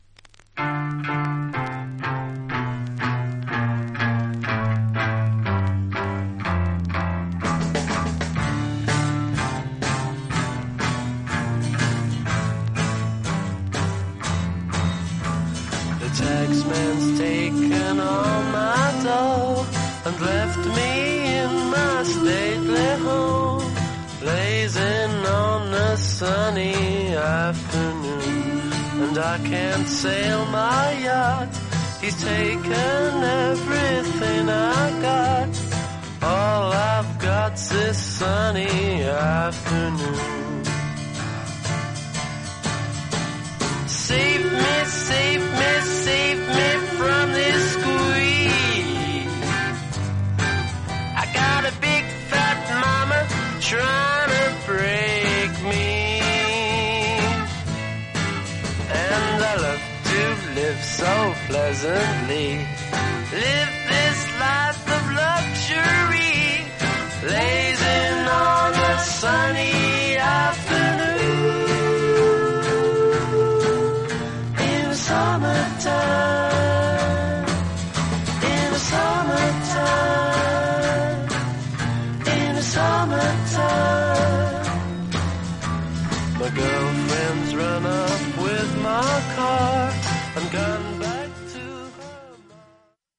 盤面は結構薄いスリキズがあり、チリパチノイズも入ります。
実際のレコードからのサンプル↓